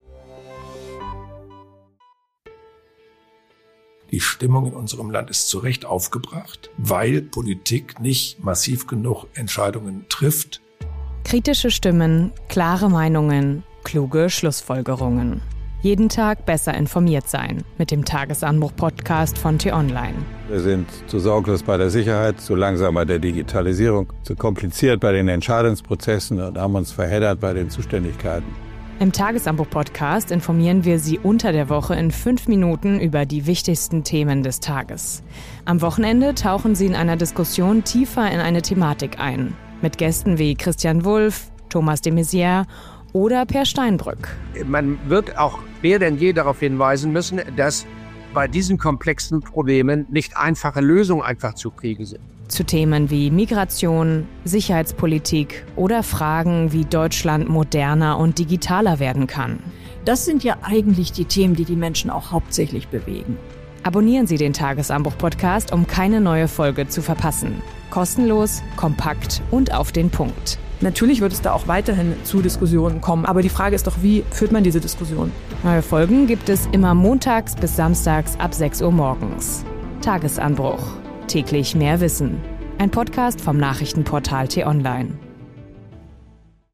Trailer
Ein Podcast vom Nachrichtenportal t-online.